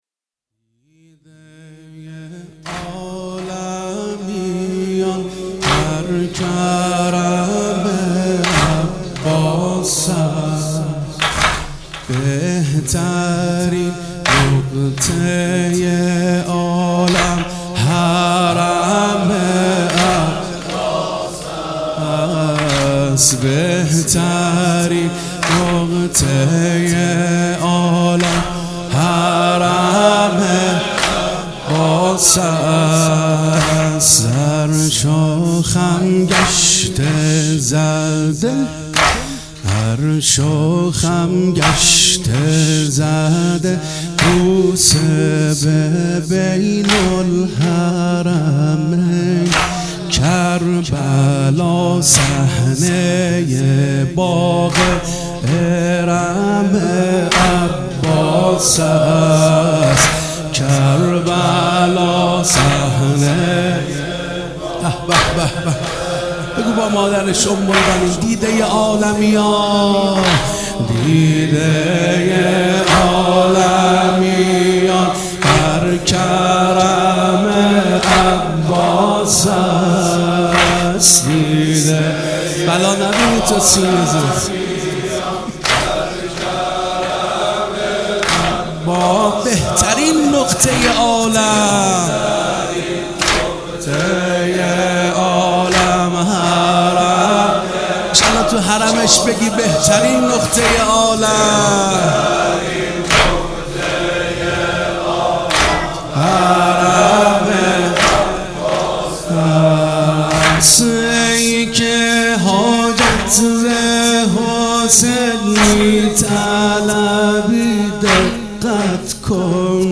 مناسبت : شهادت حضرت فاطمه زهرا سلام‌الله‌علیها1